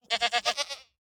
Minecraft Version Minecraft Version snapshot Latest Release | Latest Snapshot snapshot / assets / minecraft / sounds / mob / goat / idle7.ogg Compare With Compare With Latest Release | Latest Snapshot